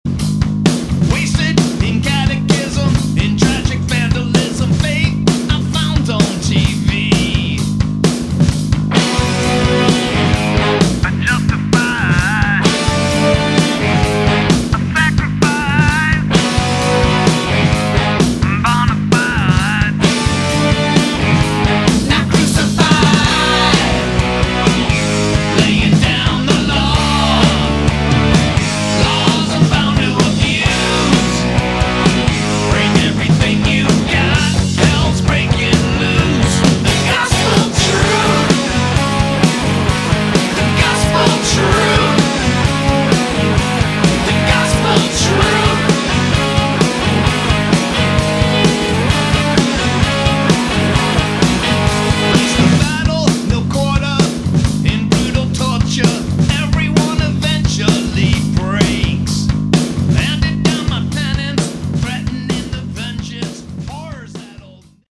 Category: Hard Rock / Bluesy Hard Rock
Electric Guitar, Lead Vocals, bass
drums
Organ, Piano